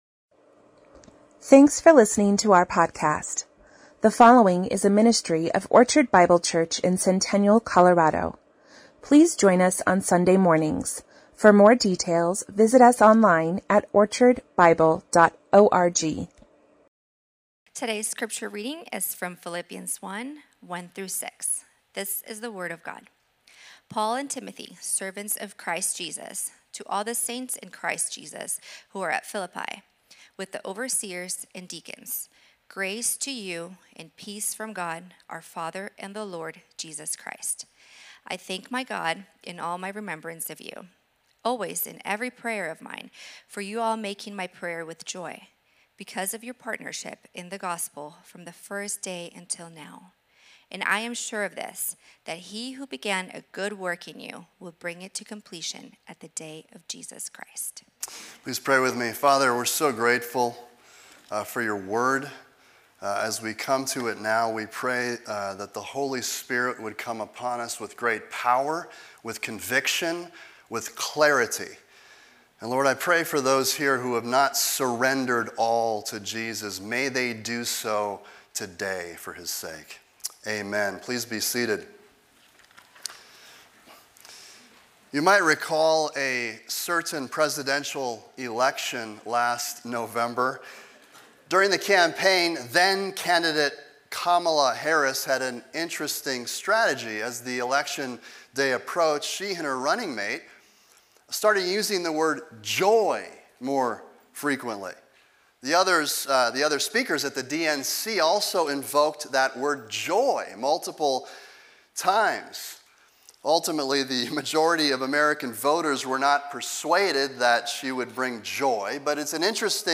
Orchard Bible Church Sermons